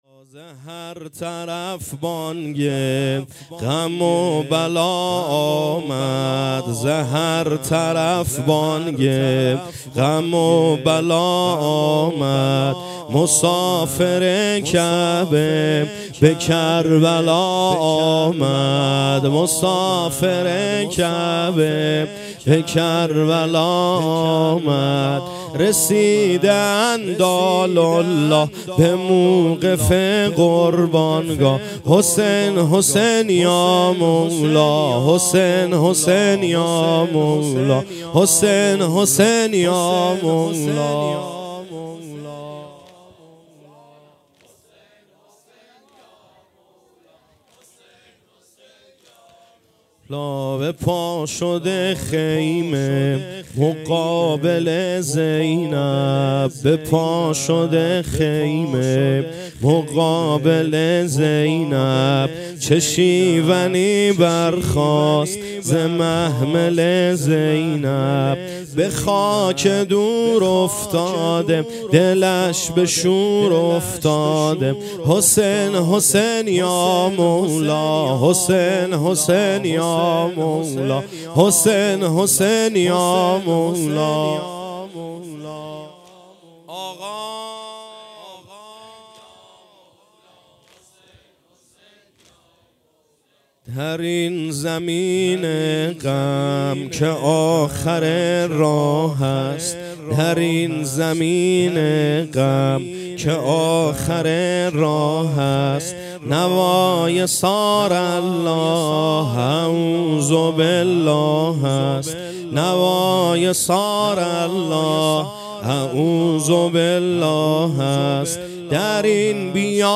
نوحه
محرم ۱۴۰۲ - شب دوّم